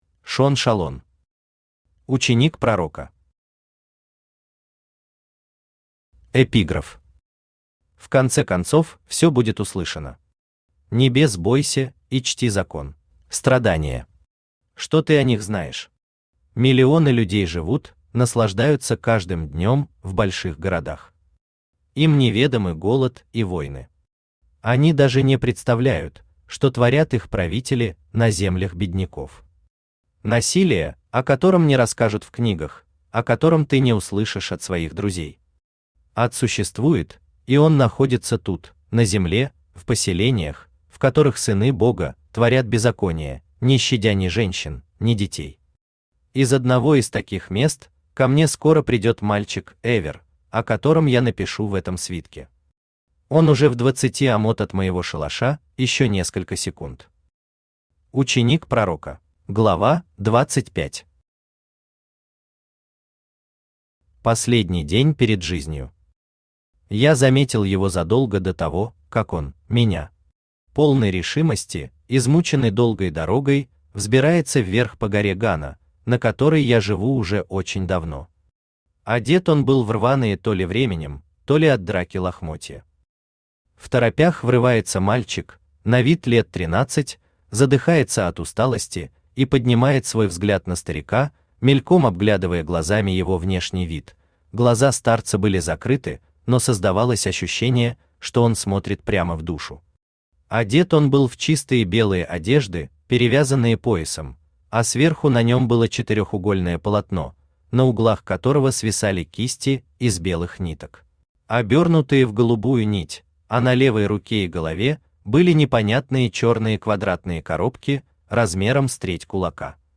Aудиокнига Ученик пророка Автор Шон Шалон Читает аудиокнигу Авточтец ЛитРес.